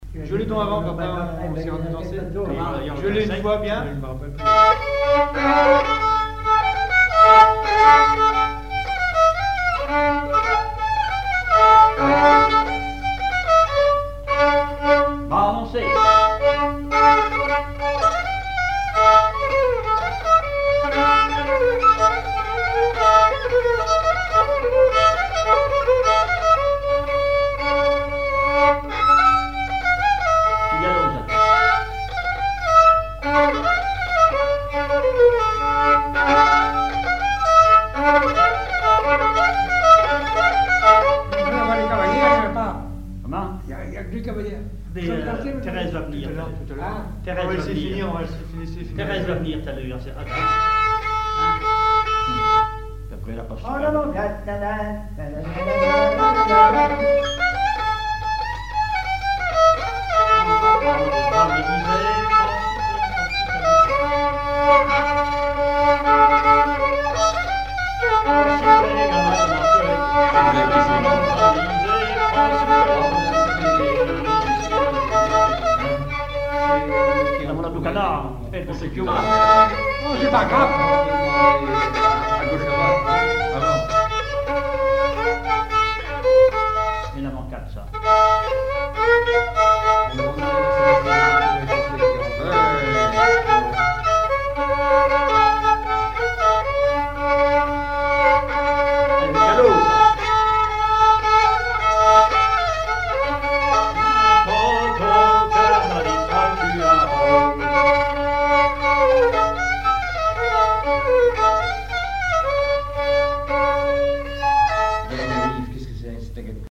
Couplets à danser
branle : avant-deux
chansons populaires et instrumentaux
Pièce musicale inédite